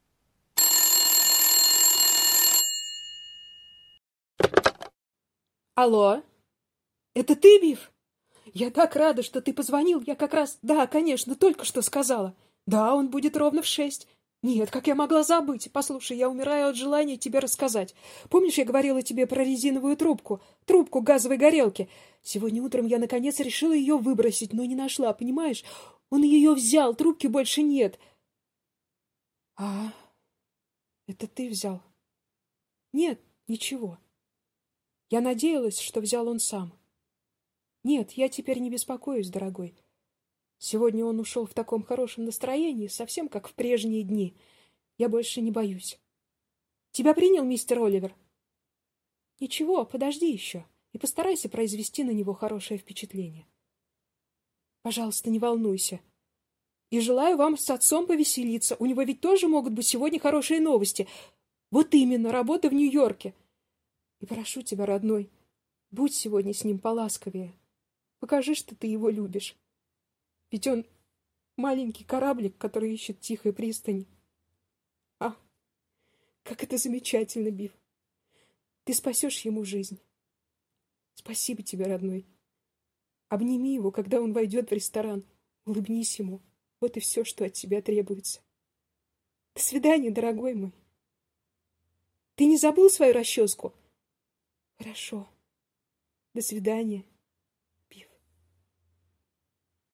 Игровая озвучка